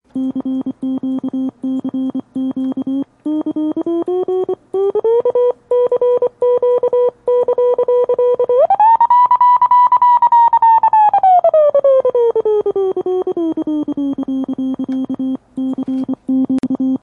homebrew side-tone oscillator
This is a sound of homebrew simple side-tone oscillator with a frequency ranging 200- to 1000 Hz. Keying of the tone is achieved by a mute transistor and the key-click is much reduced with a CR filter on its base circuit. The accompanying high tone is a side-tone from a piezo buzzer of my elec-key for the reference.